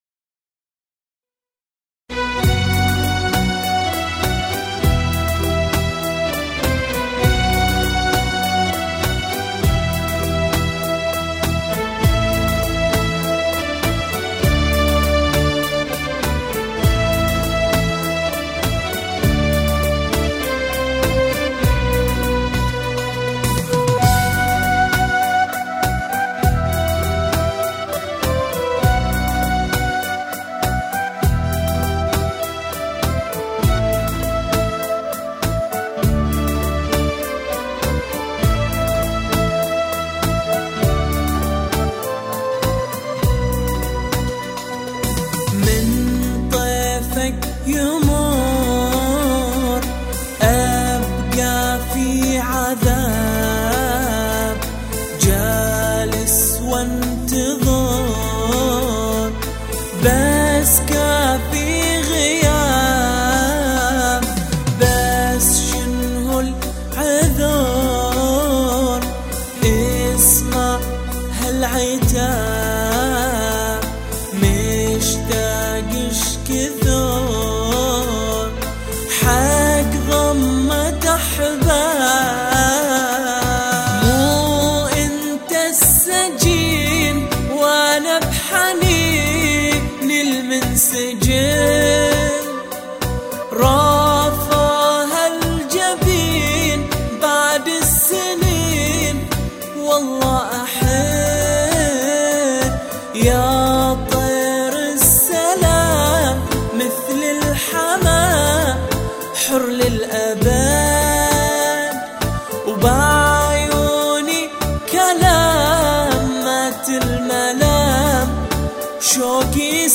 نشيد
أناشيد بحرينية